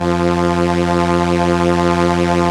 Index of /90_sSampleCDs/Roland LCDP09 Keys of the 60s and 70s 1/STR_ARP Strings/STR_ARP Solina